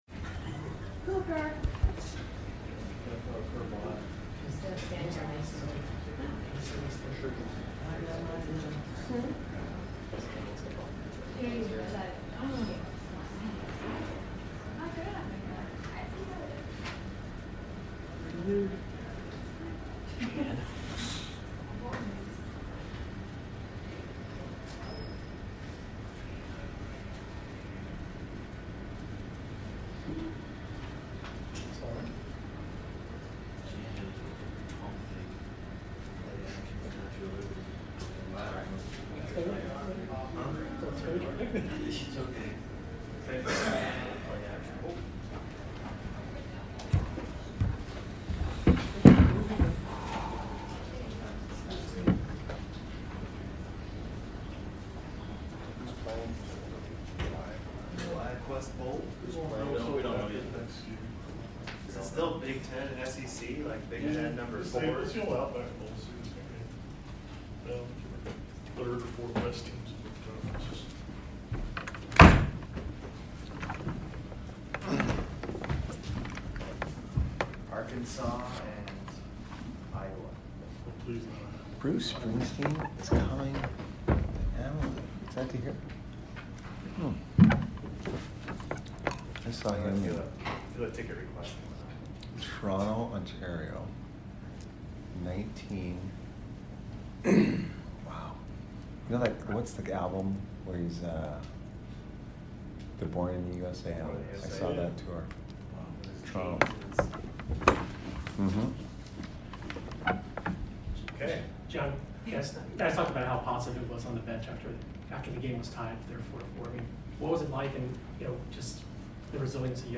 Head Coach Jon Cooper Post Game 11/15/22 vs DAL